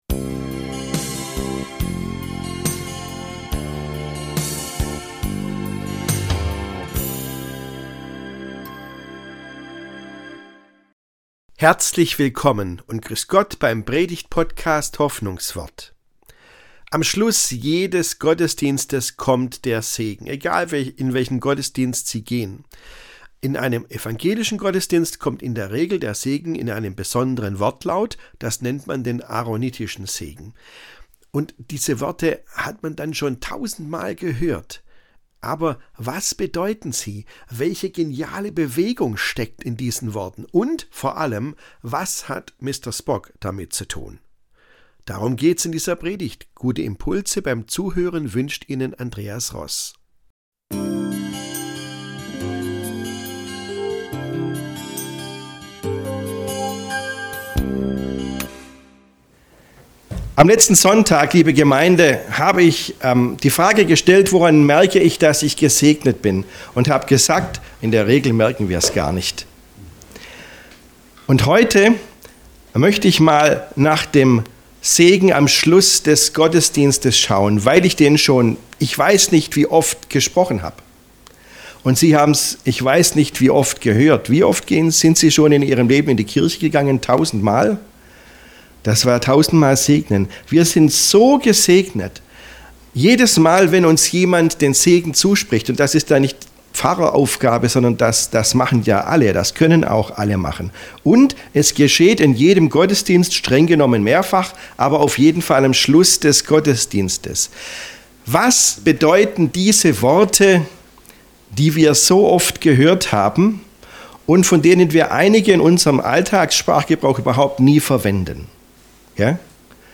Am Schluss kommt der Segen ~ Hoffnungswort - Predigten